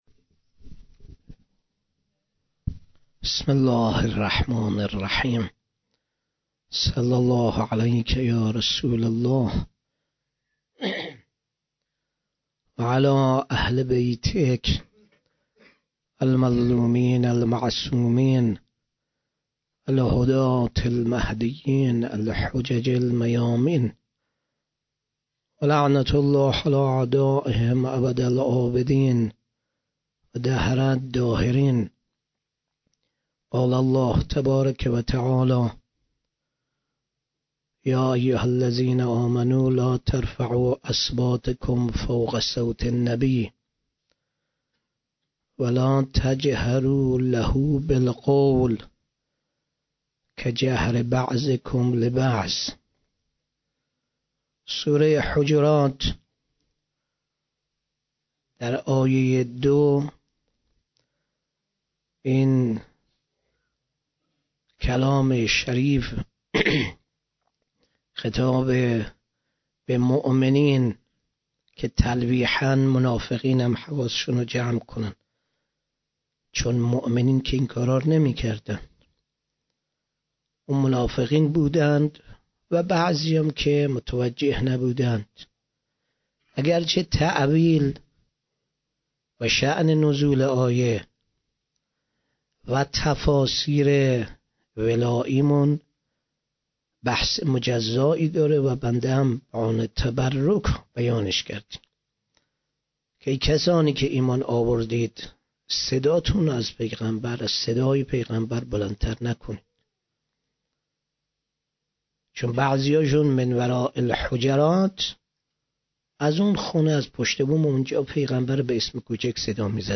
8 بهمن 96 - غمخانه بی بی شهربانو - سخنرانی